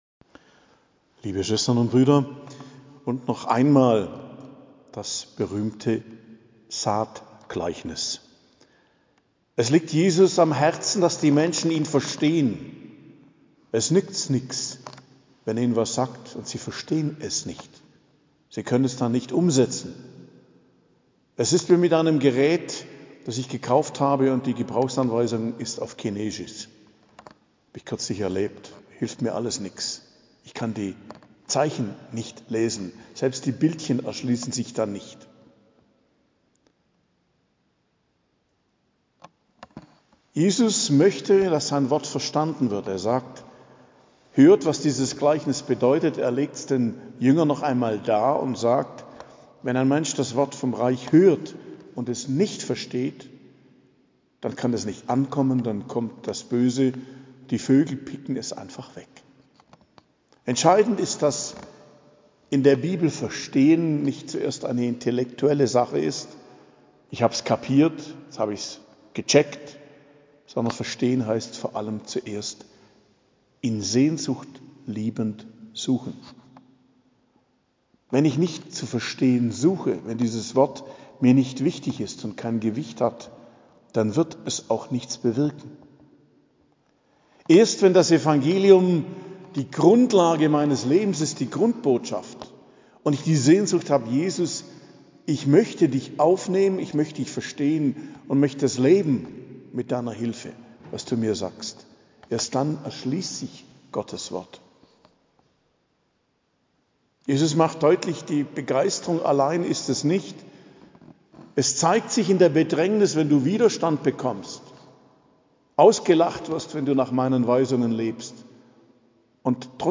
Predigt am Freitag der 16. Woche i.J., 28.07.2023 ~ Geistliches Zentrum Kloster Heiligkreuztal Podcast